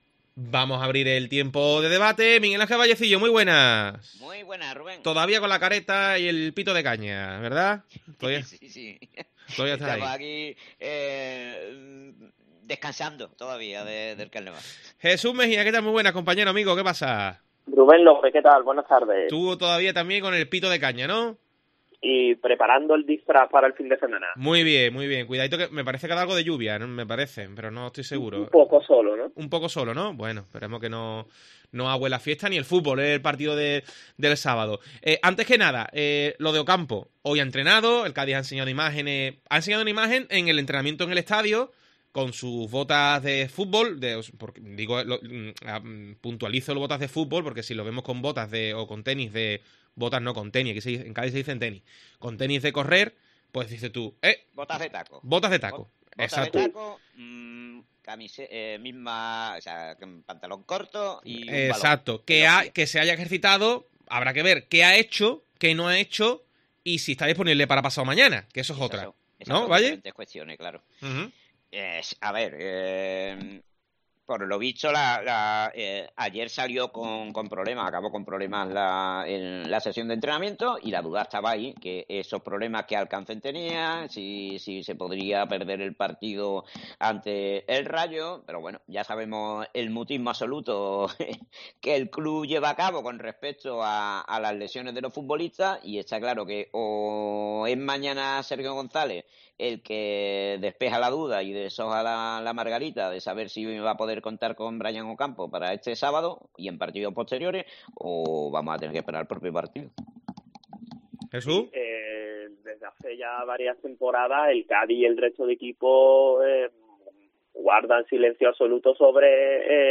Escucha DEPORTES COPE con el tiempo de debate y análisis sobre el Cádiz CF